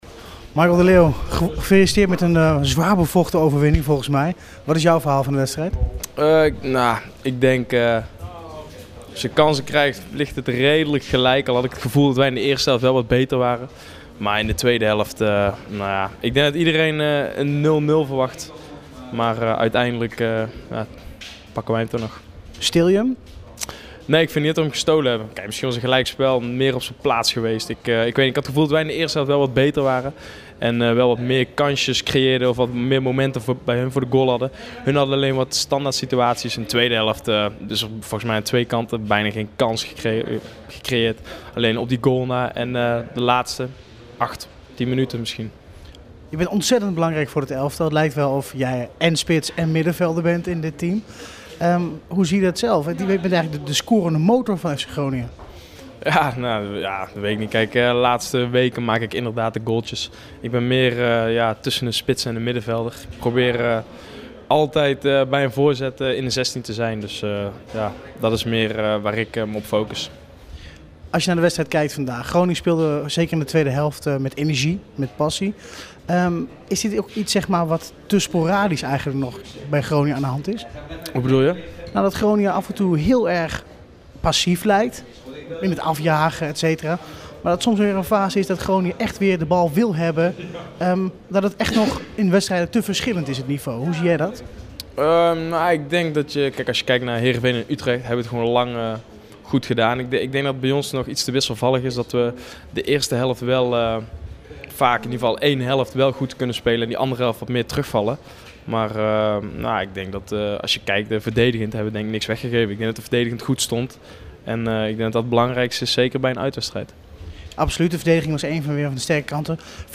Matchwinner Michael de Leeuw in gesprek met verslaggever